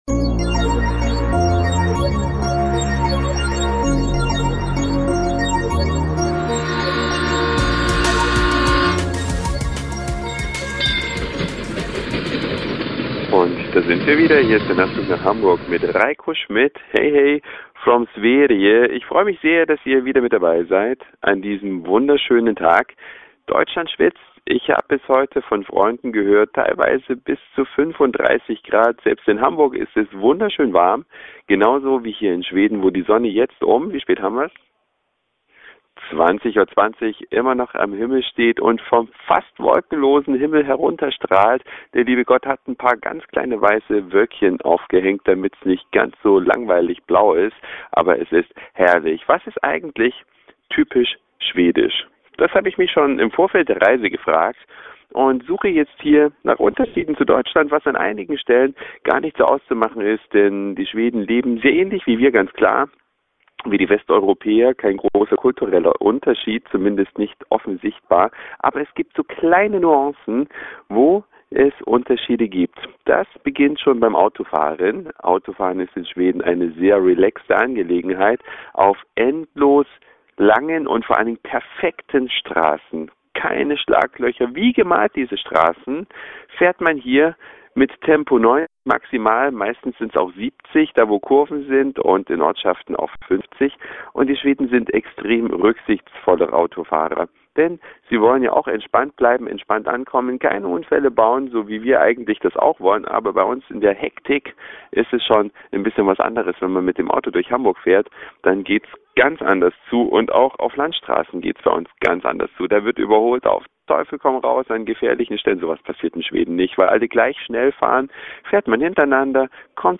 Eine Reise durch die Vielfalt aus Satire, Informationen, Soundseeing und Audioblog.
Unterwegs auf Schwedens schönster Straße.